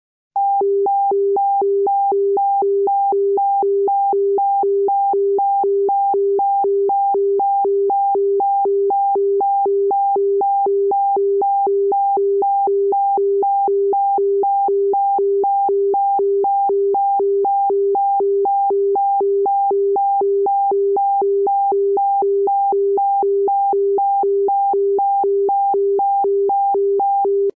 En las tablas se representa gráficamente el sonido estéreo construído a partir de tonos puros de duración 250ms y de frecuencias 400Hz (pitido grave) y 800Hz (pitido agudo).
Sin embargo, como has visto, existe tono agudo y tono grave en ambos lados.